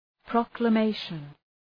Προφορά
{,prɒklə’meıʃən}